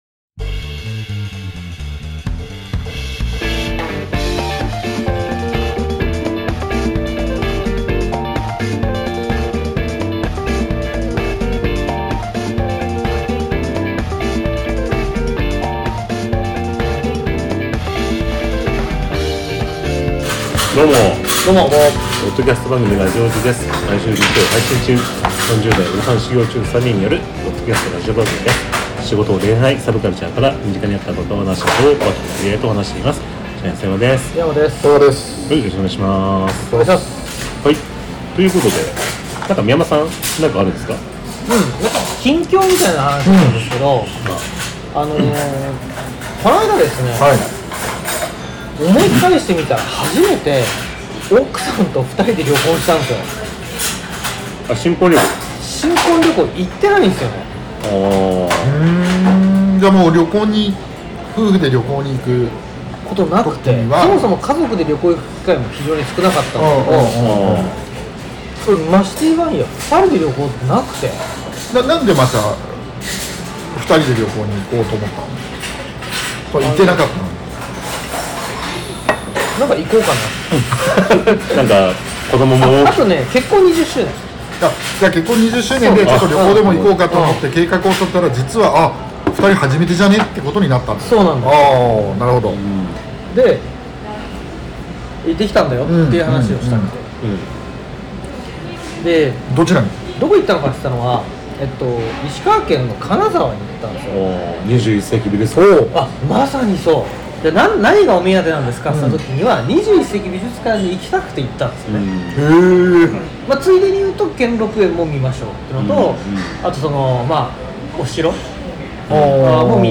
30代オジサン初心者3人によるポッドキャストラジオ。仕事、恋愛、サブカルから身近にあった馬鹿話等を和気あいあいと話しています。